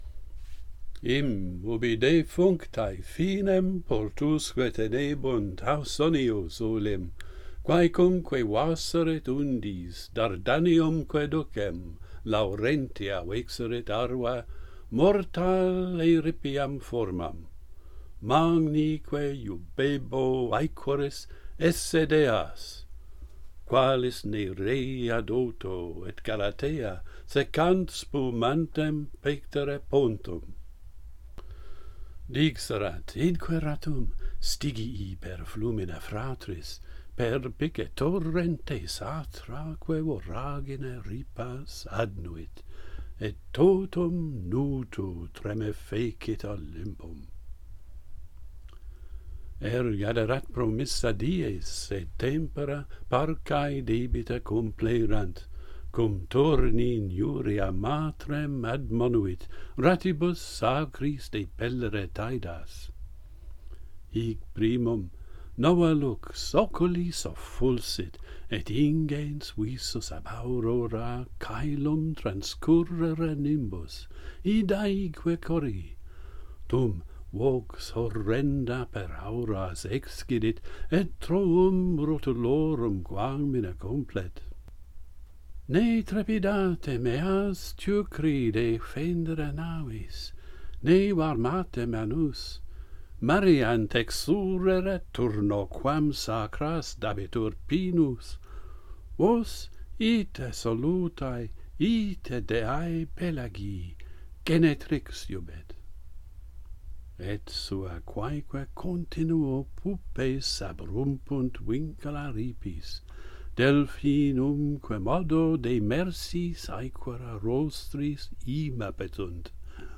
Aeneas's ships are transformed - Pantheon Poets | Latin Poetry Recited and Translated